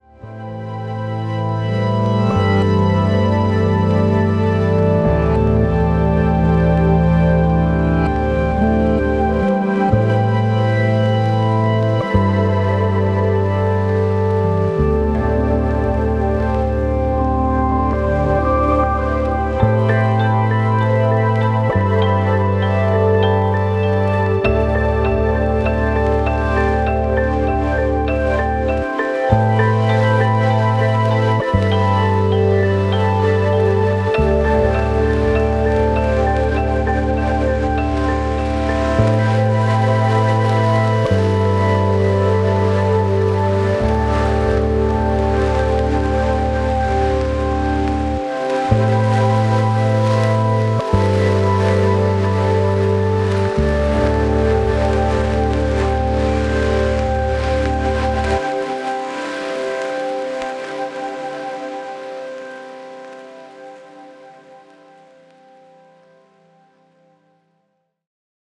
有機的なニューエイジ